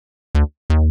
New Bassline Pack